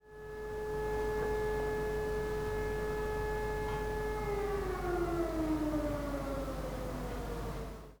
Sirene
Eine Sirene ist eine Einrichtung zur akustischen Alarmierung, in der Regel durch einen charakteristischen an- und abschwellenden Heulton.
sirene